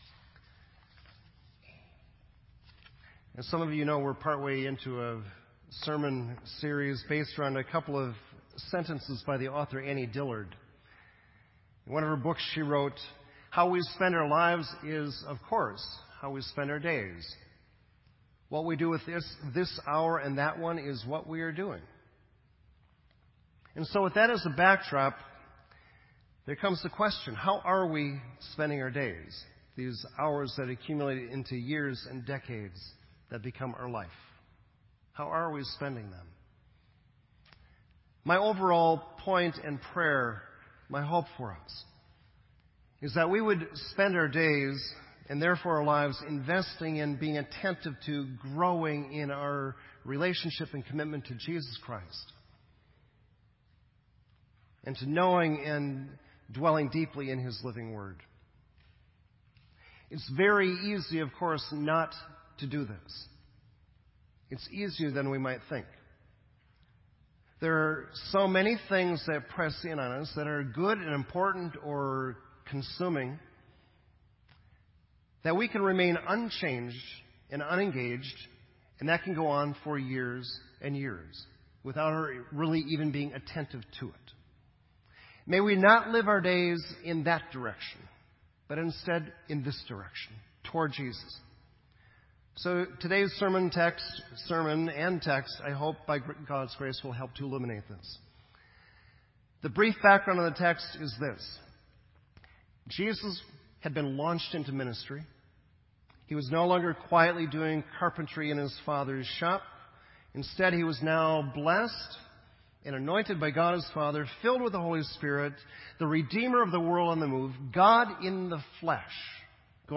This entry was posted in Sermon Audio on July 6